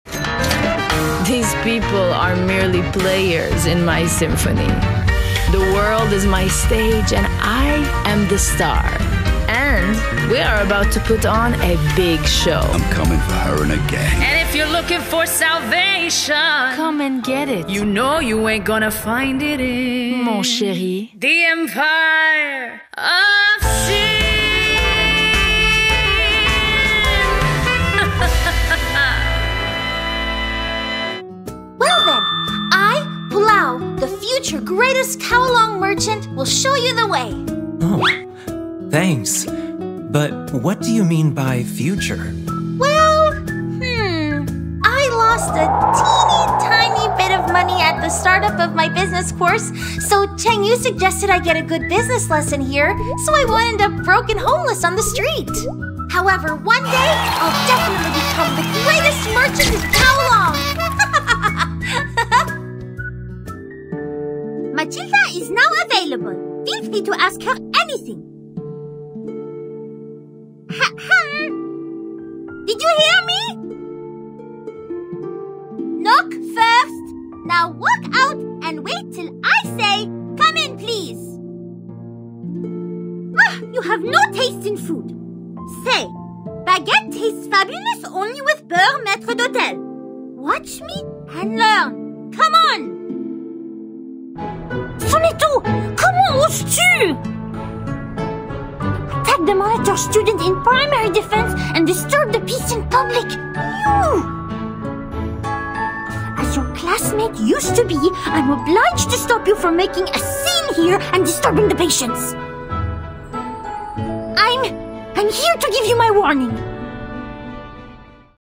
Has Own Studio
GAMING 🎮
Video_Game_Demo.mp3